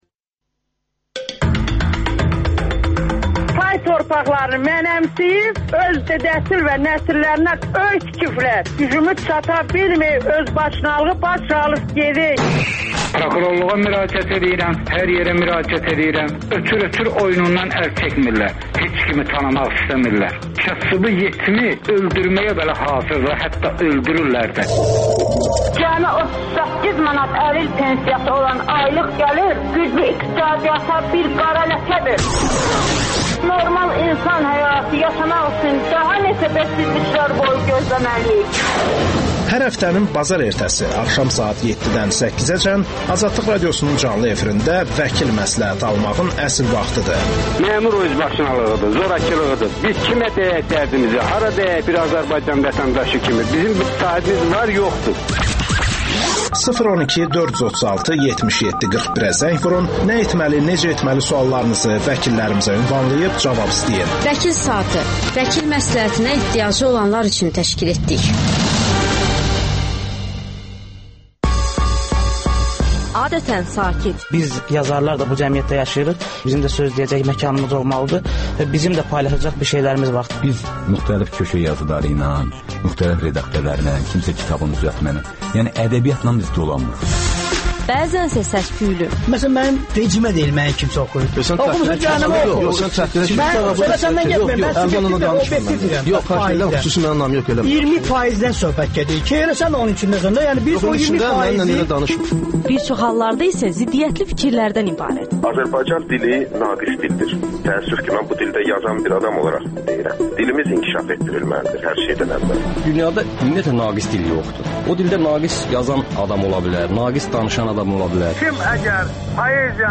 Pen klub - Əkrəm Əylisli studiyaya ən sevimli kitabı ilə gəldi